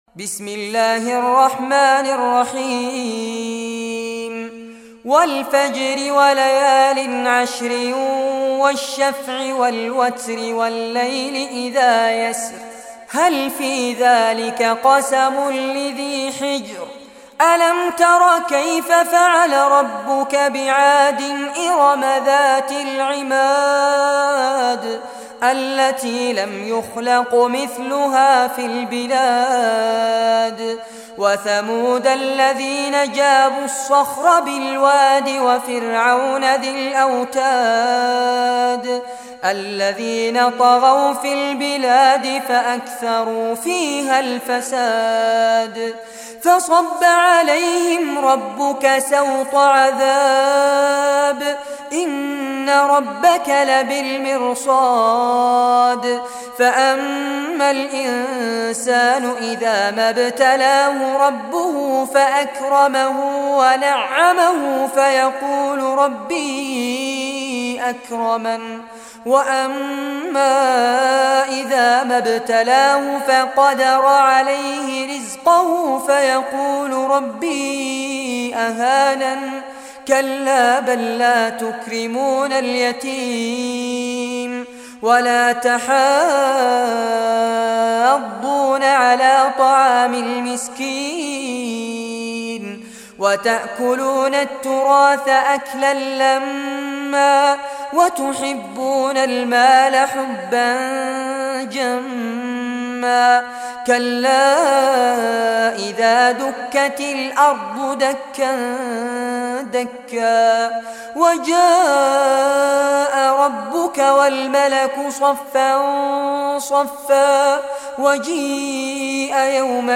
Surah Al-Fajr Recitation by Fares Abbad
Surah Al-Fajr, listen or play online mp3 tilawat / recitation in Arabic in the beautiful voice of Sheikh Fares Abbad.
89-surah-fajr.mp3